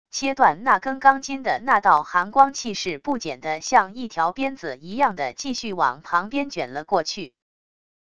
切断那根钢筋的那道寒光气势不减的像一条鞭子一样的继续往旁边卷了过去wav音频生成系统WAV Audio Player